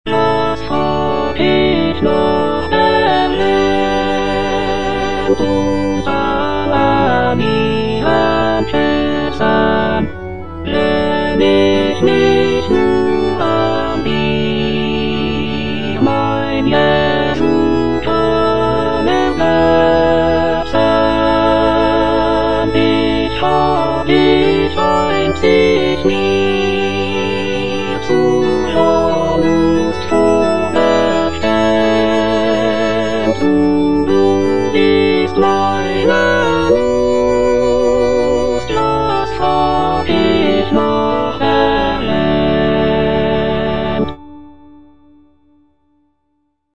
Choralplayer playing Cantata
J.S. BACH - CANTATA "SEHET, WELCH EINE LIEBE" BWV64 Was frag' ich nach der Welt - Alto (Emphasised voice and other voices) Ads stop: auto-stop